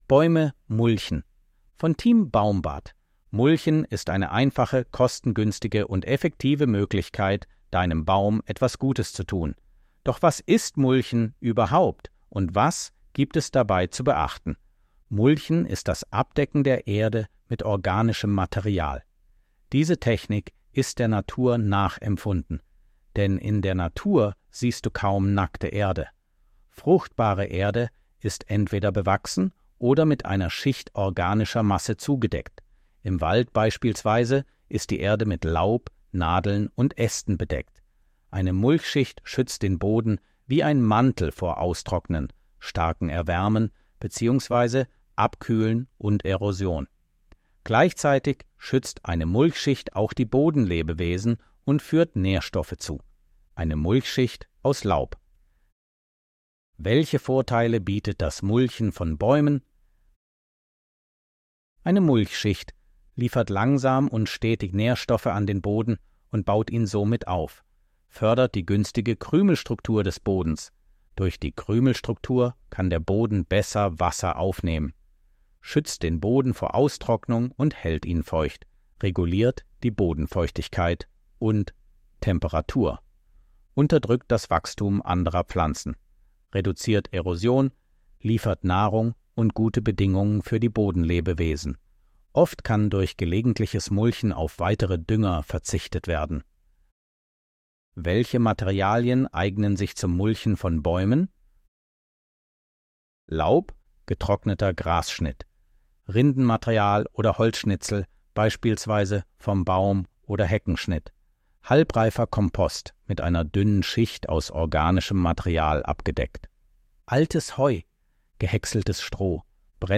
von Team baumbad 24.07.2024 Artikel vorlesen Artikel vorlesen Mulchen ist eine einfache, kostengünstige und effektive Möglichkeit, deinem Baum etwas Gutes zu tun.